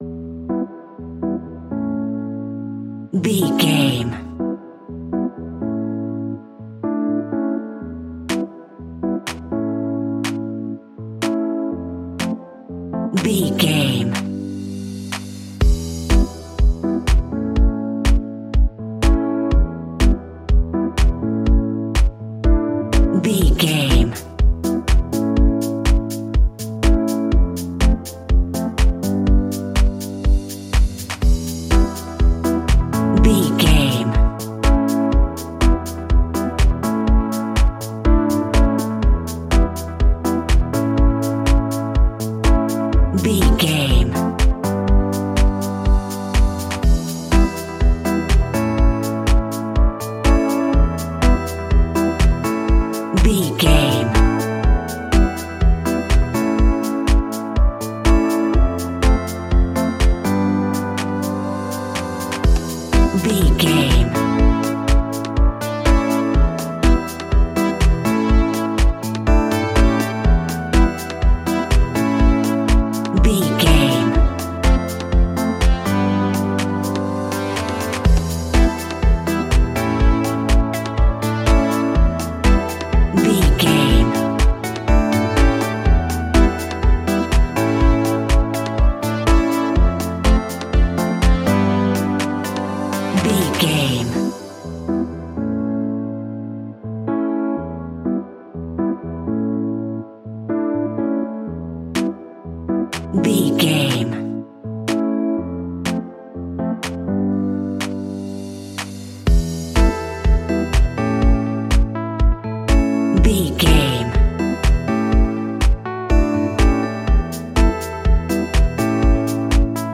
Aeolian/Minor
uplifting
energetic
bouncy
synthesiser
drum machine
electric piano
funky house
nu disco
groovy
synth bass